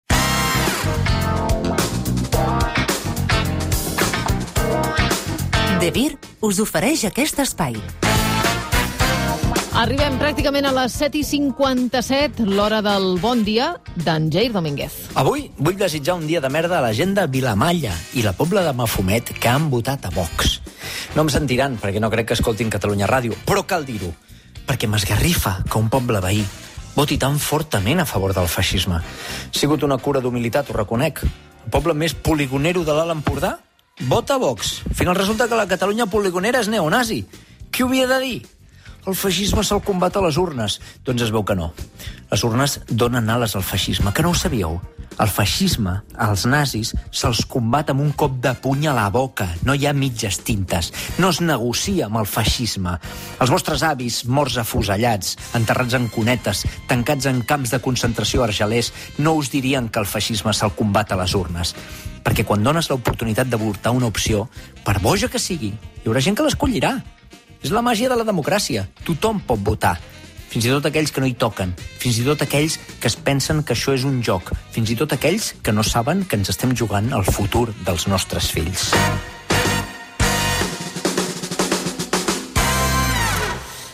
El tribunal jutjava per un delicte d’odi l’escriptor i guionista Jair Domínguez per haver dit: “El feixisme, els nazis, es combaten amb un cop de puny a la boca.” Tot plegat en una secció en to d’humor, “Bon dia de merda”, d’El matí de Catalunya Ràdio.
L’escena, força absurda –amb publicitat d’una marca de jocs de taula–, ha estat ben breu perquè la secció amb prou feines durava un minut.